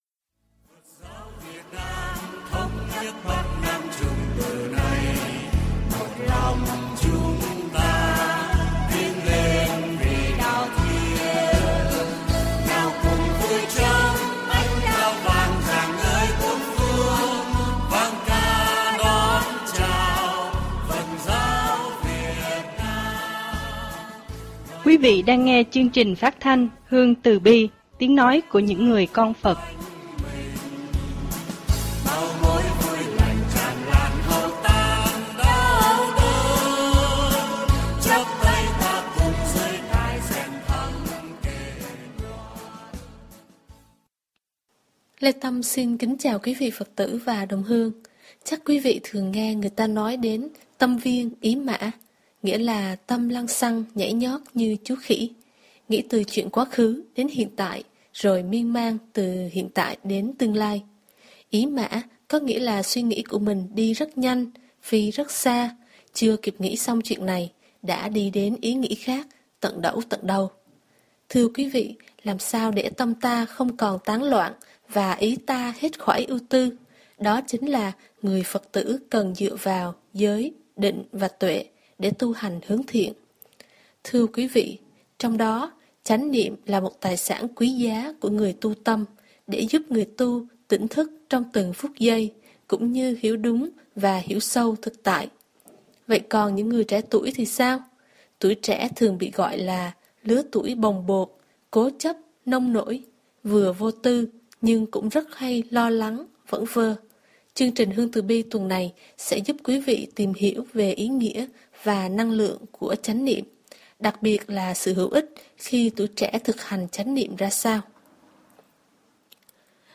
Lễ Thù Ân Giọng tụng